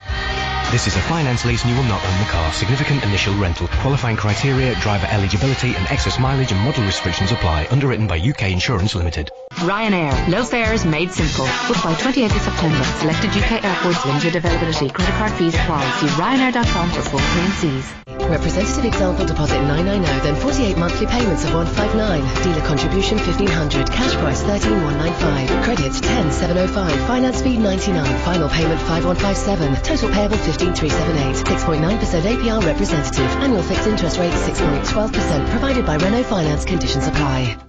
It is the ends of three ads from the same ad break today.